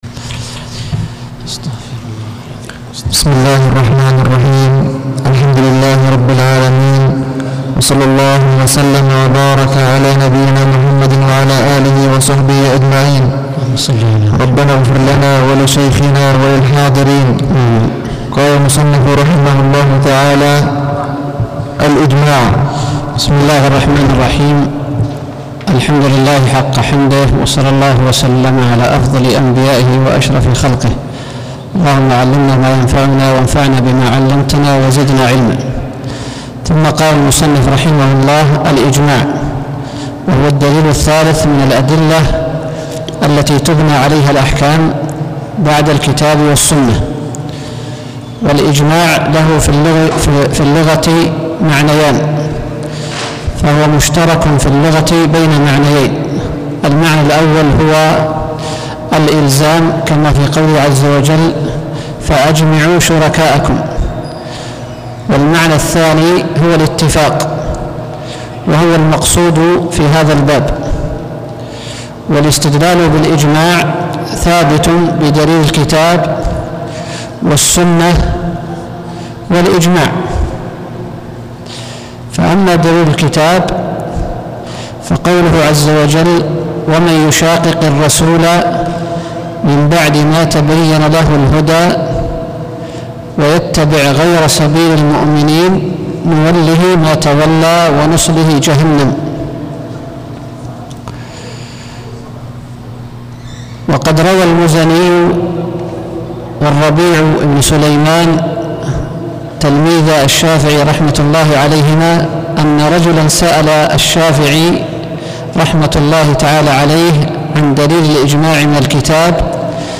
الدرس السادس عشر: الإجماع – قول الصحابي.